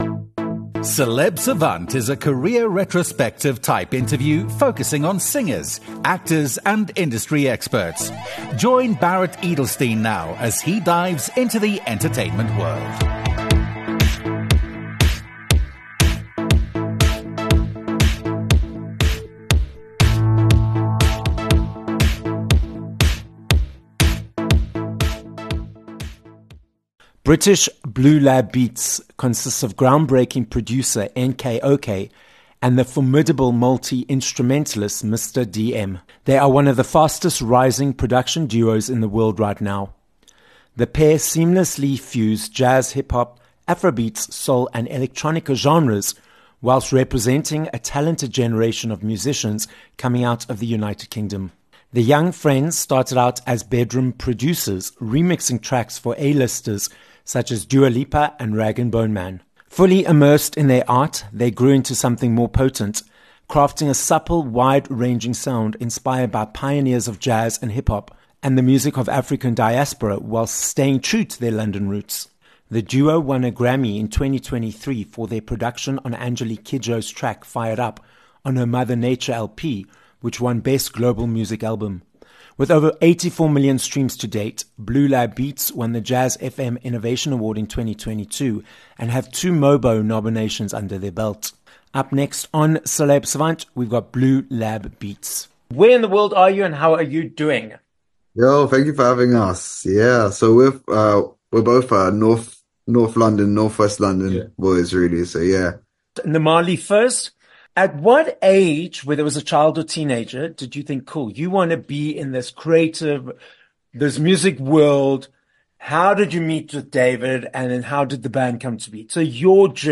Blue Lab Beats - a British Grammy Award winning instrumental production duo, NK-OK and Mr. DM - join us on this episode of Celeb Savant. The duo explain how they came together to create music, their experience of winning a Grammy for producing Angelique Kidjo, and where their group name comes from.